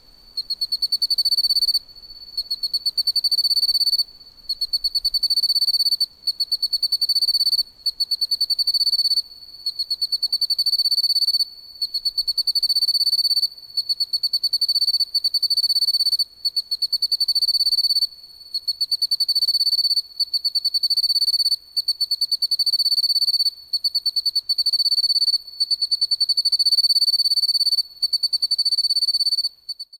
リュウキュウサワマツムシ（リュウキュウマツムシ）の鳴き声
渓流沿いに生息しており、夜の渓流で鳴いています。
ピィピィピリリリリ・・・・という鳴き声はとても清らかです。
＊ 沖縄の動物・植物達のコーナー 録音：SonyリニアPCMレコーダーPCM-M10 国頭村にて録音
ryukyusawamatumushi-call.mp3